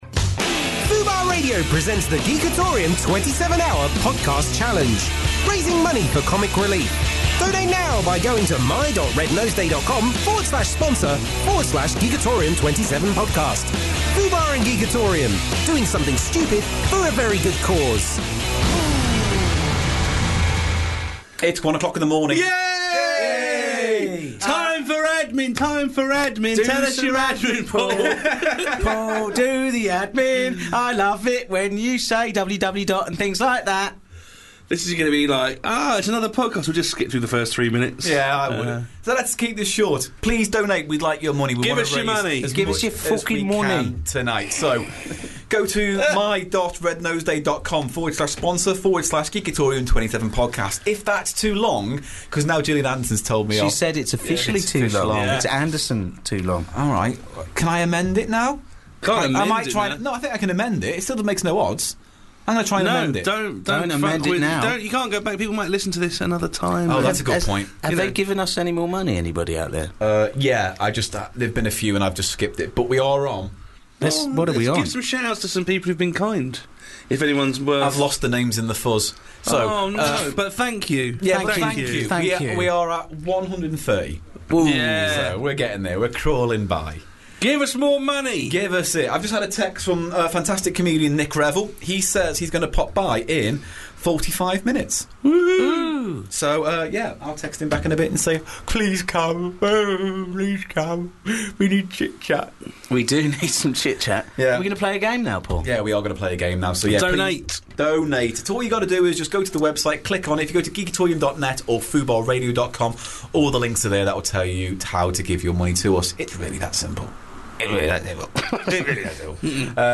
A 27 hour comedy marathon broadcast in aid of Comic Relief! Packed with games, sketches, fund raising challenges and chat.